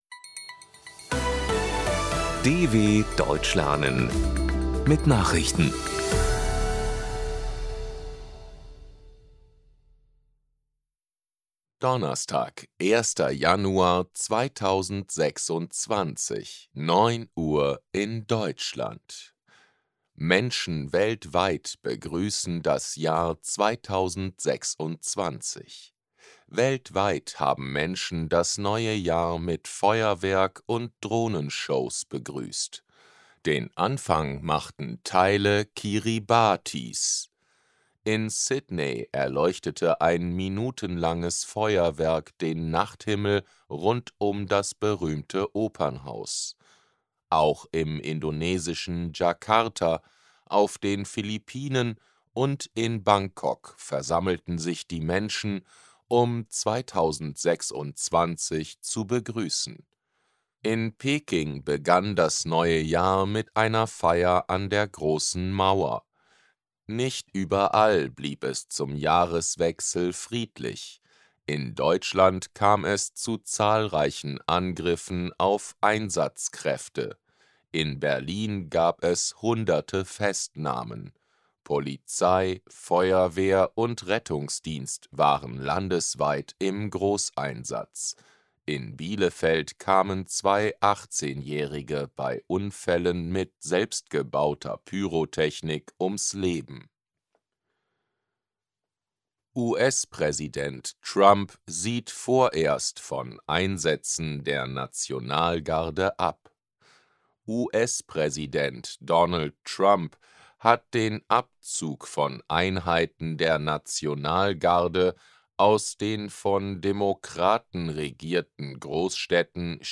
01.01.2026 – Langsam Gesprochene Nachrichten
Trainiere dein Hörverstehen mit den Nachrichten der DW von Donnerstag – als Text und als verständlich gesprochene Audio-Datei.